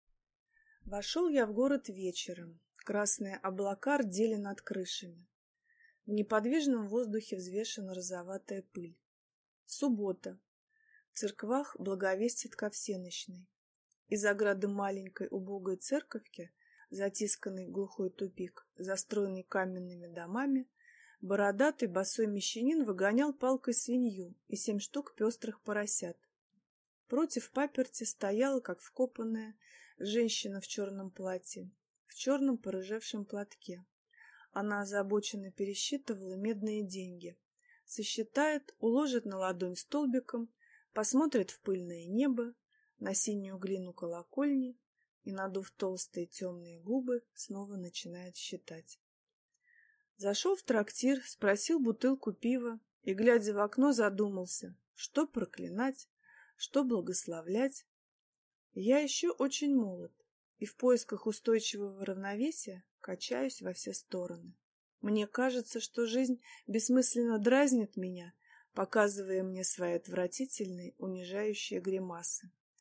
Аудиокнига Мамаша Кемских | Библиотека аудиокниг